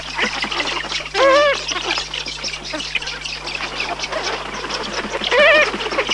Eurasian Spoonbill
Eurasian-Spoonbill.mp3